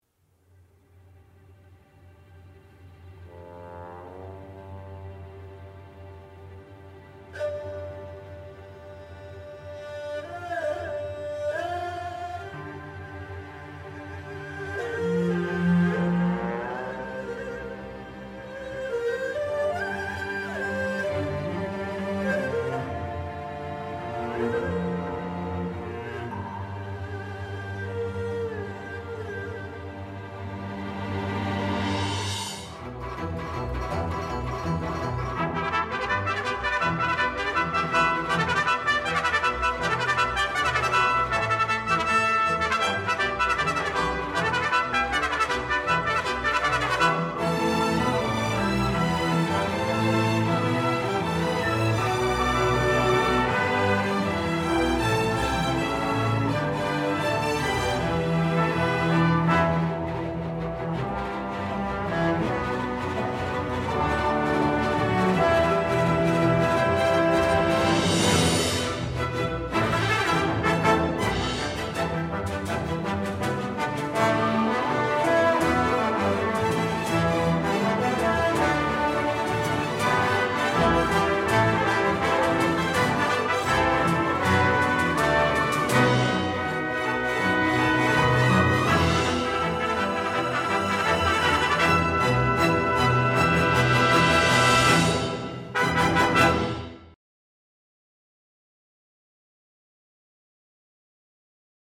モンゴル人の故郷、果てしない大草原に誘う、闊達な曲。